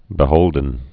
(bĭ-hōldən)